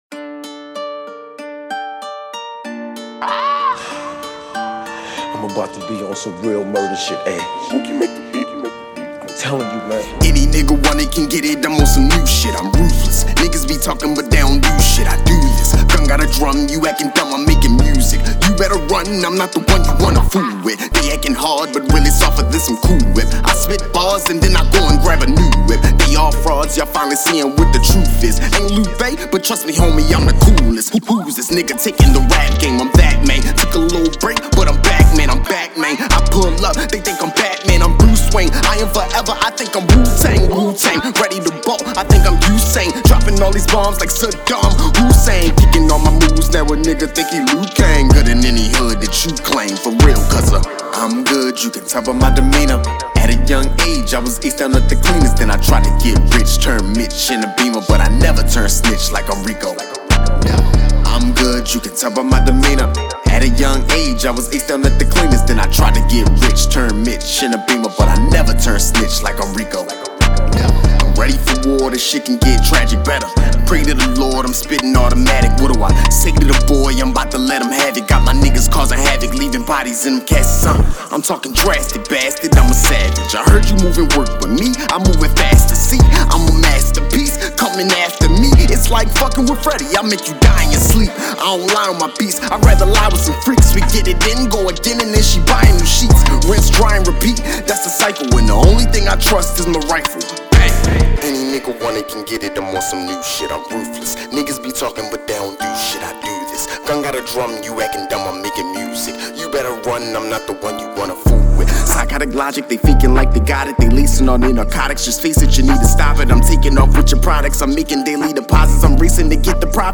Hiphop
With a emotional guitar melody over have 808s and busy drums